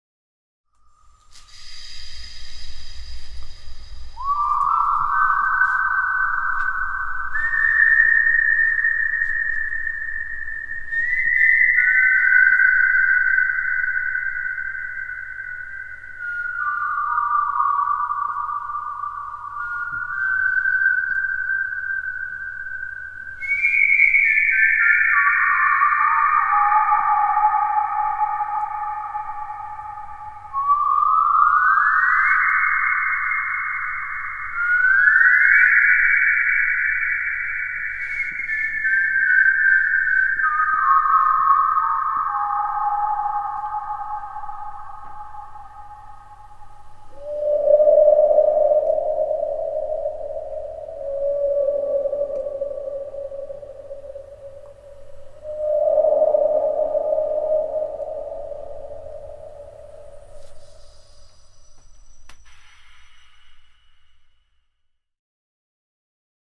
interactive installation for computer, four loudspeakers, one microphone,
Whisteling,
fischiettando.mp3